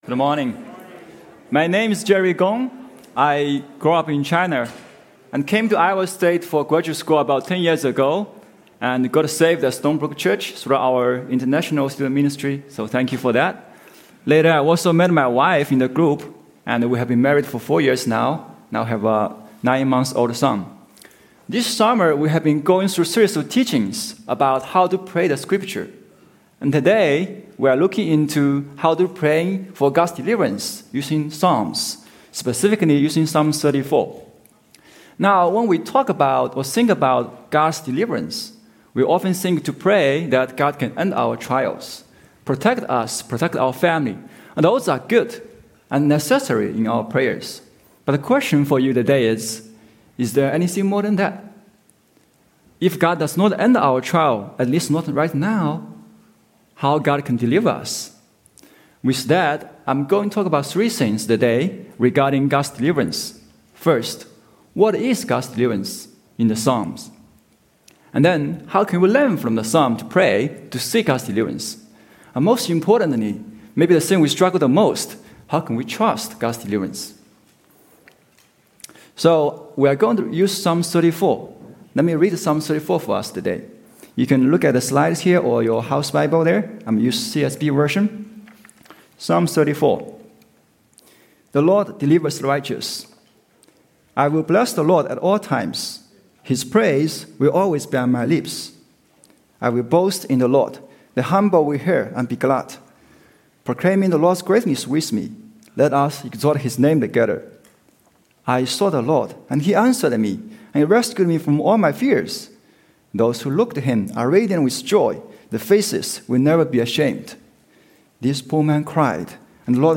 Guest Speaker – Praying Psalms of Deliverance (Psalm 34)